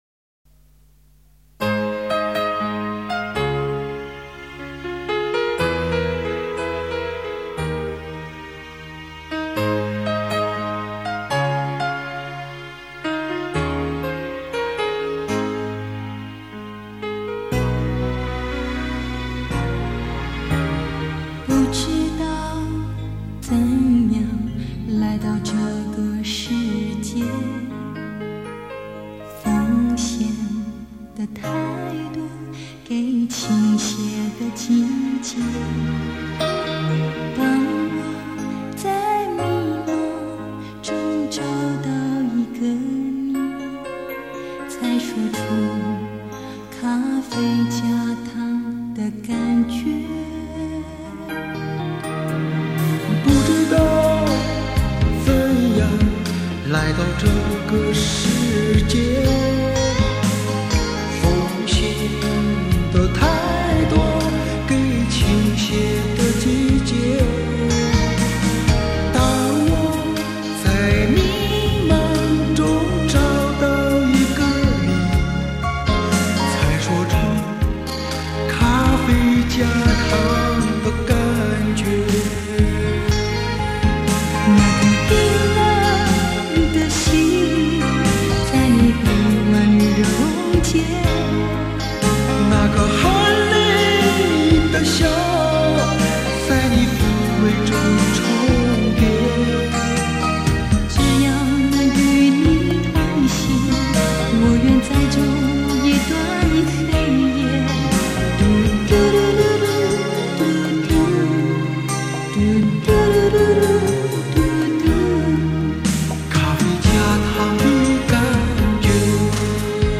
【磁带转录】